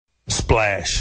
splash_SKLOP5y.mp3